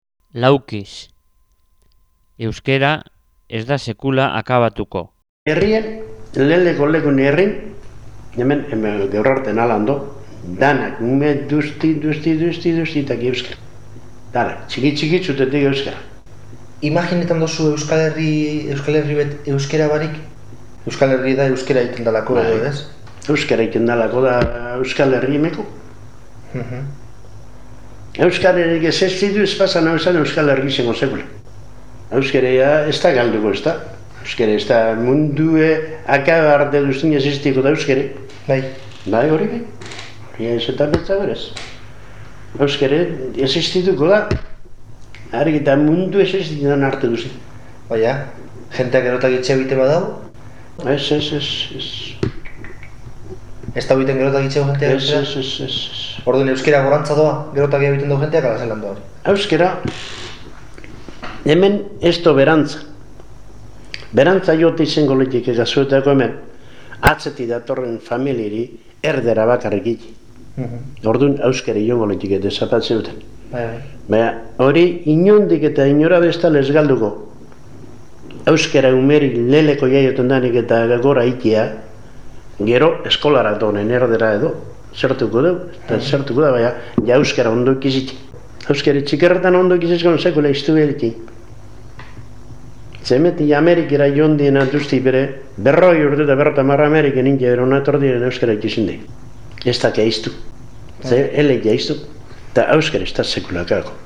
1.5 LAUKIZ
Laukiz.mp3